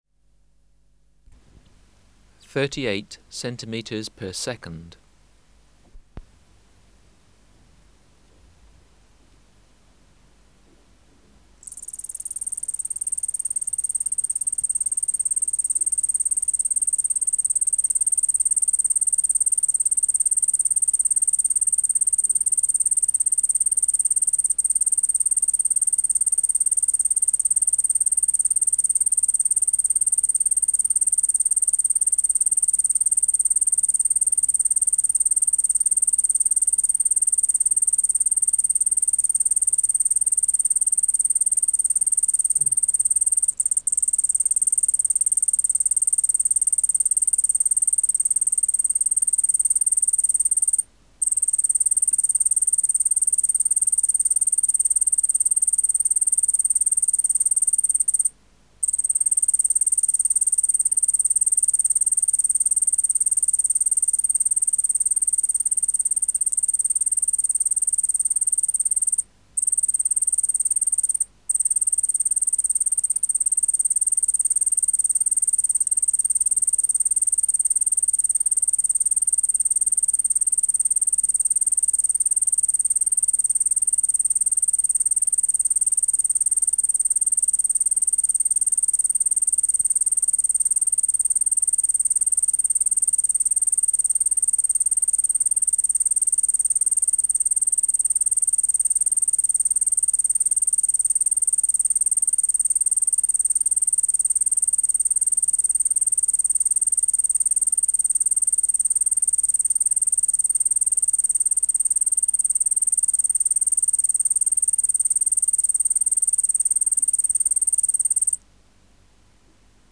417:2 Allonemobius allardi(549b) | BioAcoustica
Recording Location: BMNH Acoustic Laboratory
Reference Signal: 1 kHz for 10 s
Substrate/Cage: Small recording cage
Distance from Subject (cm): 15 Filter: Low pass, 24 dB per octave, corner frequency 20 kHz